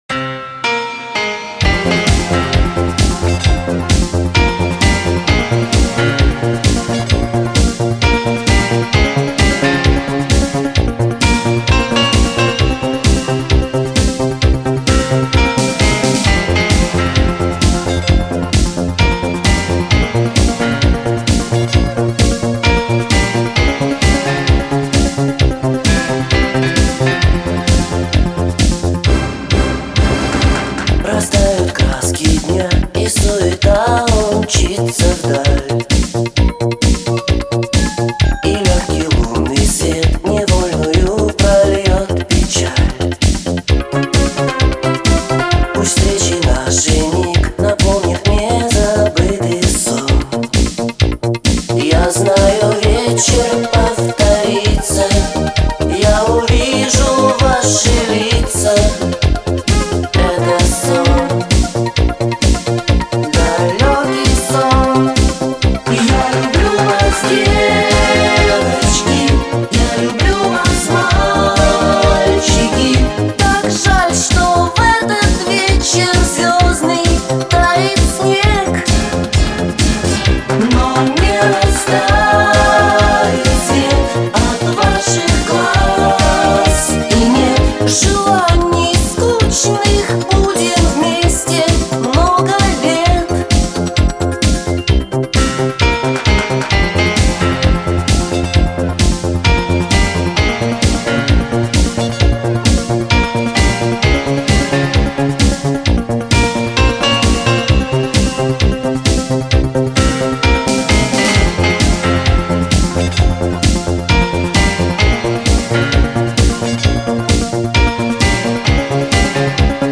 кто ищет легкую и позитивную музыку